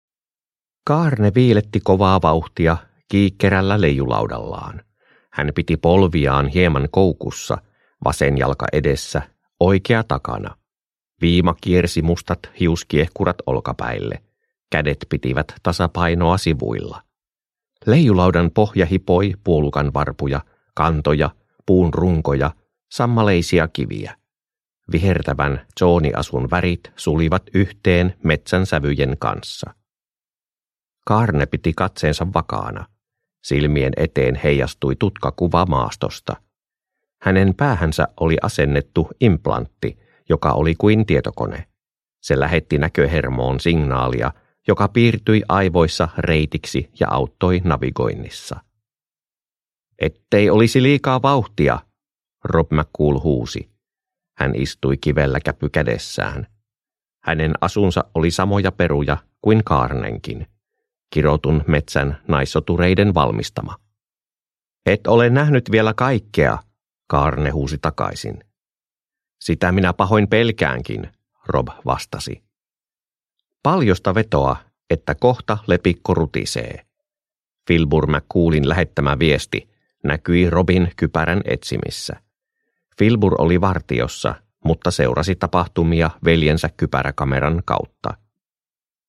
Rob McCool ja Lapponian vangit – Ljudbok